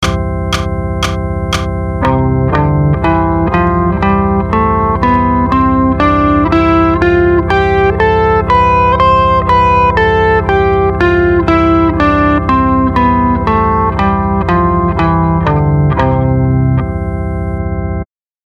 The first example is the C major scale in the 7th position which means that the lowest fretted note is on the 7th fret.
C Major/Ionian | Download
c_major.mp3